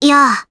Gremory-Vox_Attack3_jp.wav